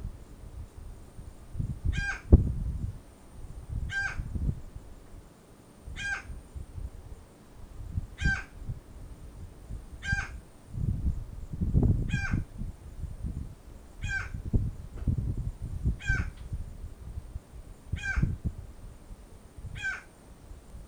dont voici le cri adulte:
galago.m4a